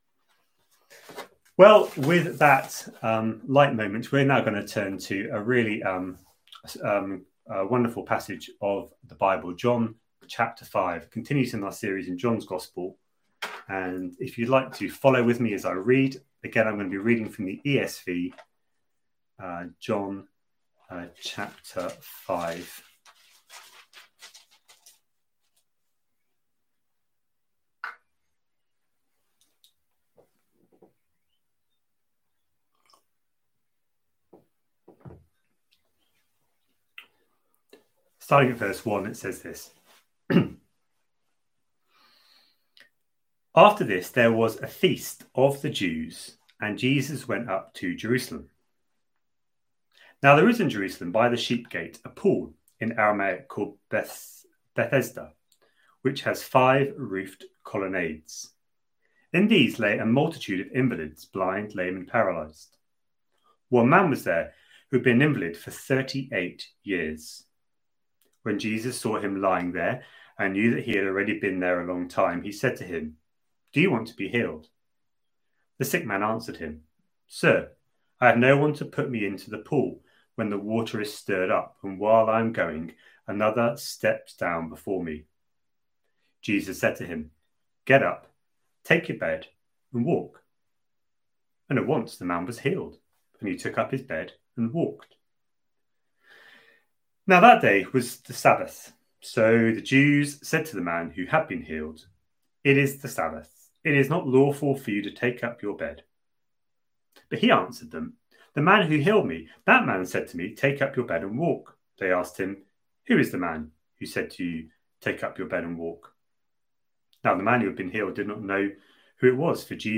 A sermon preached on 7th June, 2020, as part of our John: The Father's Son series.